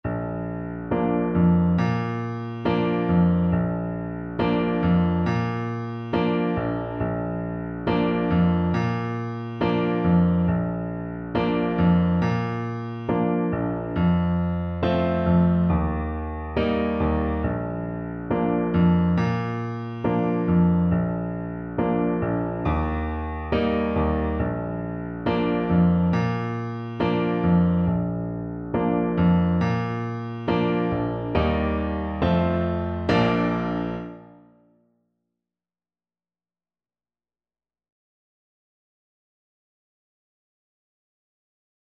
Moderato
4/4 (View more 4/4 Music)
Traditional (View more Traditional Trombone Music)
Congolese